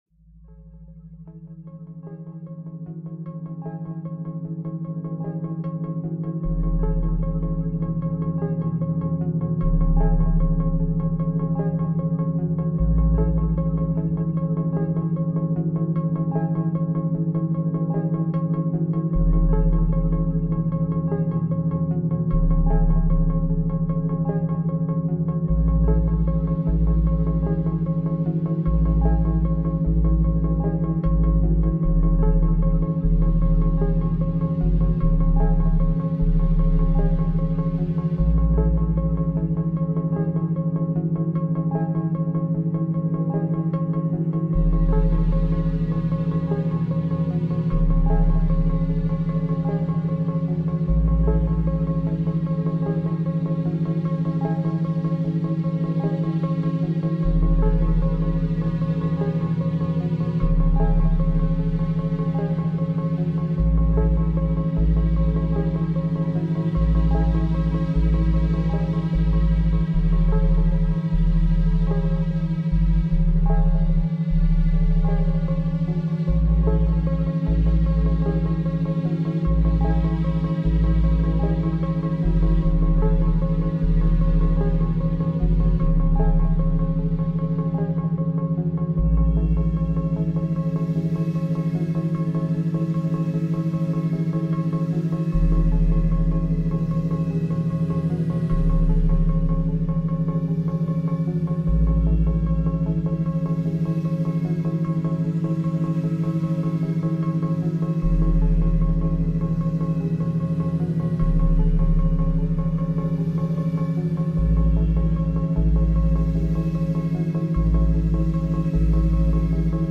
Background Sounds, Programming Soundscapes, Coding Beats